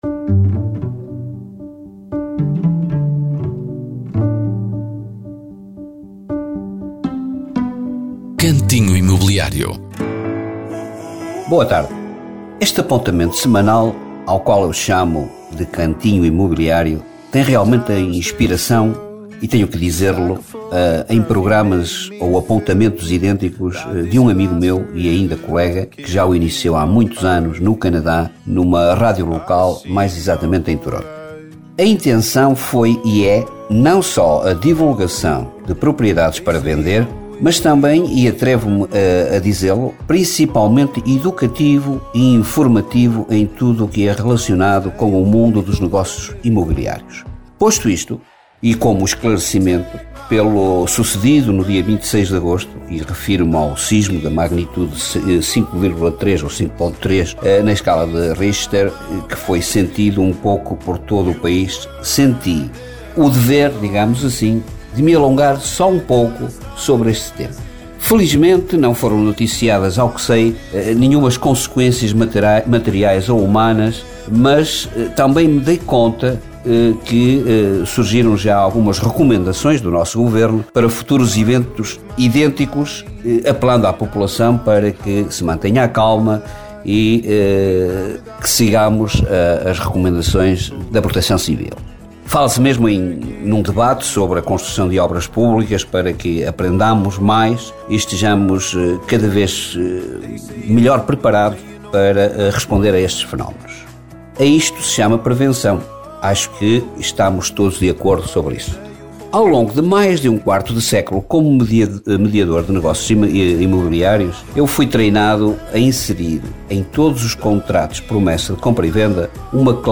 Cantinho Imobiliário é uma rubrica semanal da Rádio Caminha sobre o mercado imobiliário.